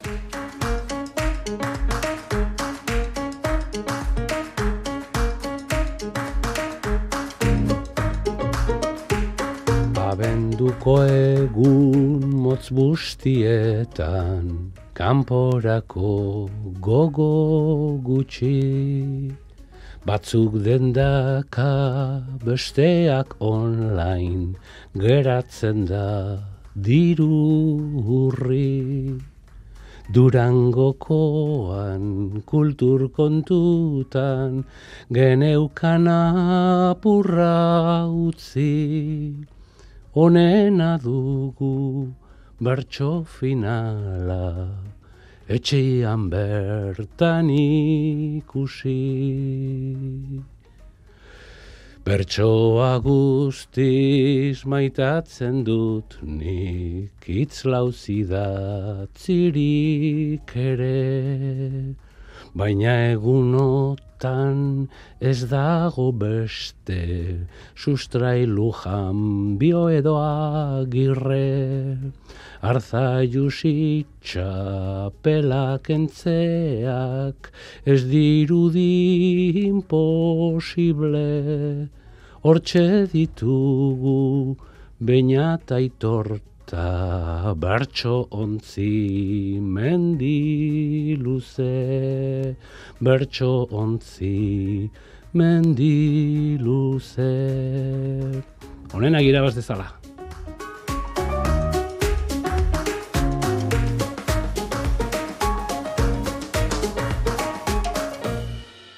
bertsotan